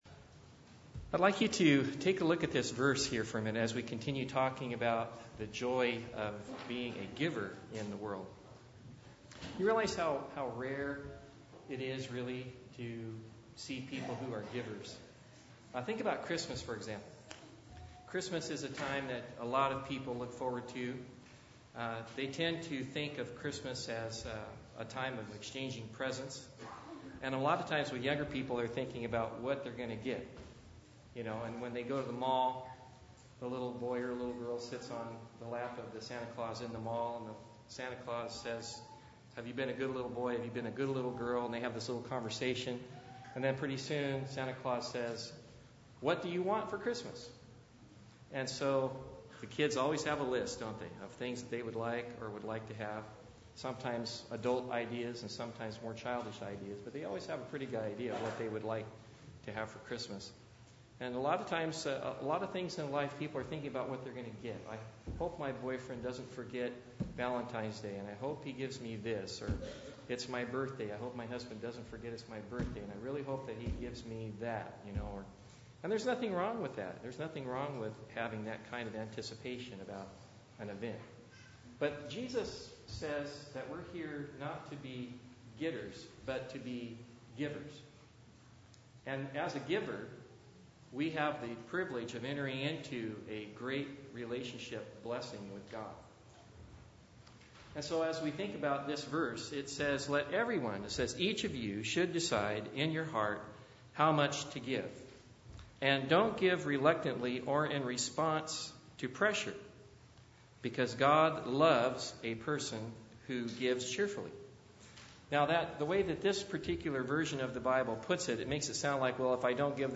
II Cor. 8:2 Service Type: Sabbath Bible Text